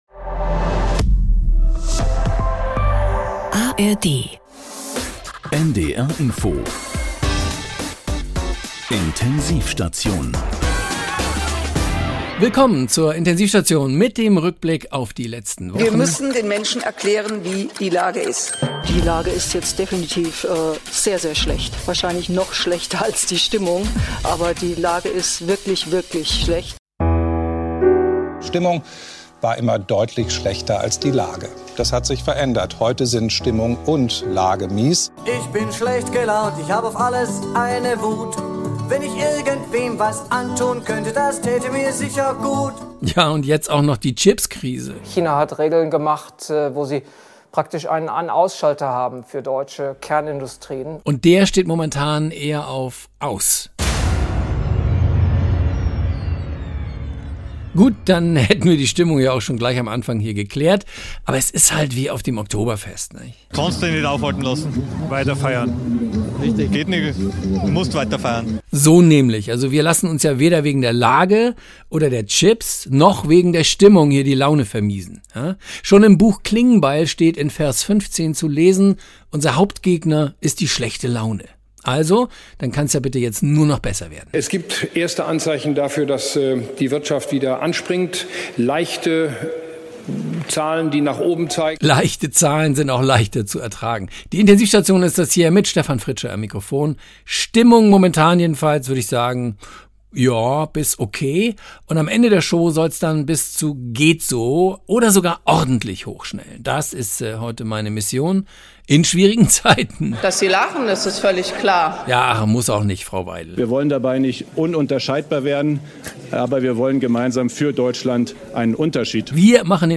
Deshalb liefert die Intensiv-Station von NDR Info genau das: aktuelle, politische Satire und Comedy - im Radio und als Podcast! Egal ob Streit in der Koalition oder Empörung in der Opposition, ob neue Rekorde bei Umfragewerten oder Kirchenaustritten, oder auch die Schlammschlacht zwischen Musk und Zuckerberg: Die Intensiv-Station nimmt jeden Montag die wichtigsten Themen aus Politik, Gesellschaft und Sport unter die Lupe und aufs Korn.